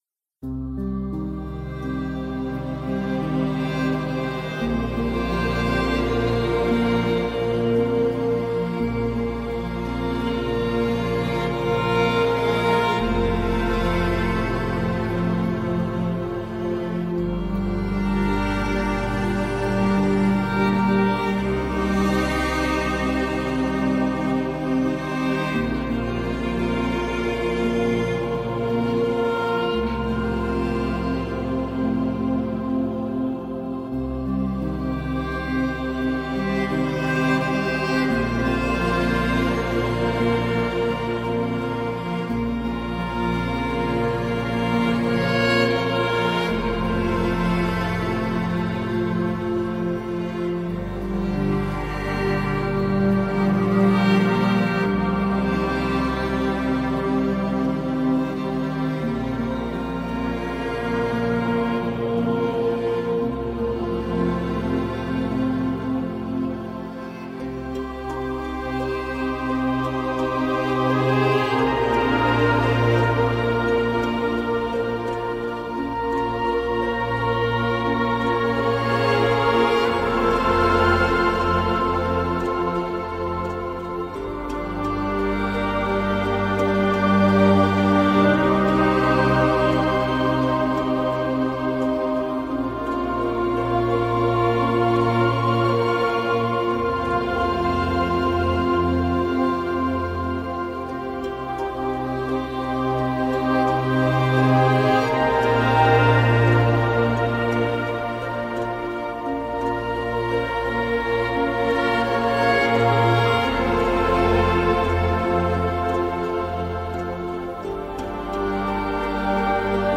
Orchestral track for RPG and cinematic.